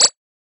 edm-perc-47.wav